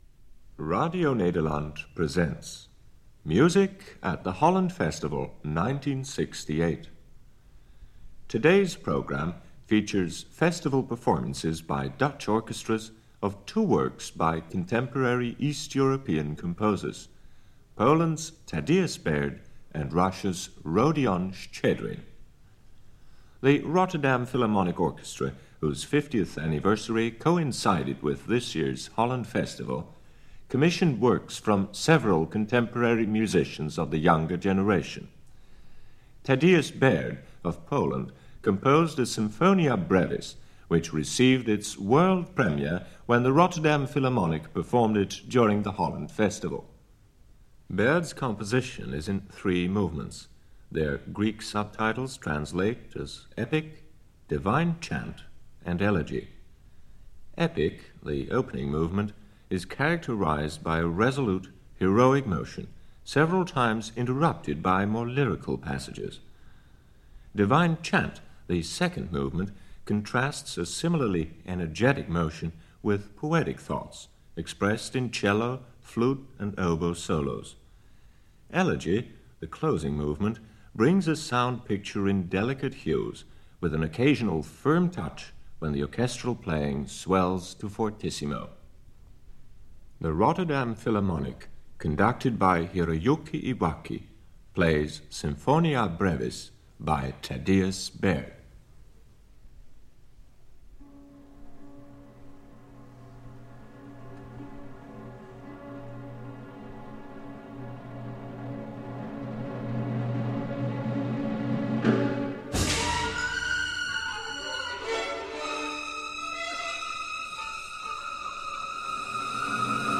Music from the 1968 Holland Festival this weekend. Two mid-twentieth century works. Starting with Sinfonia Brevis by Tadeusz Baird and concluding with Contrasts for piano and Orchestra by Rodion Shchedrin with the composer at the piano accompanied by the Amsterdam Concertgebouw, led by Gennady Rozhdestvensky. The Baird is played by the Rotterdam Philharmonic led by Hiroyuki Iwaki.